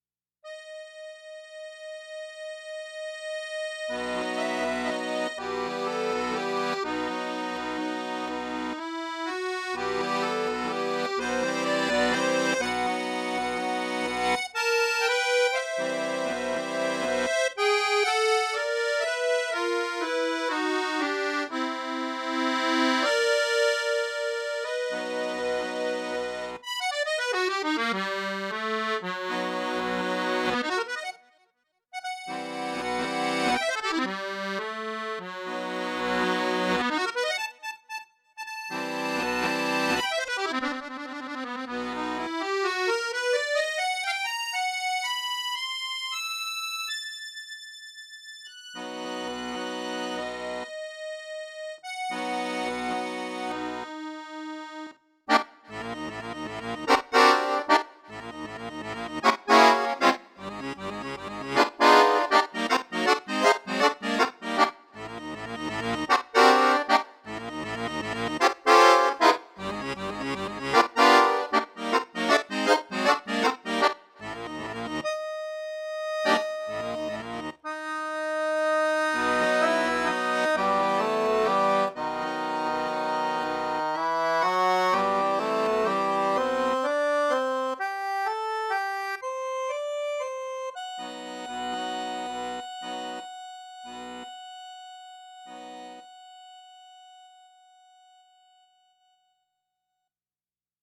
Solo performances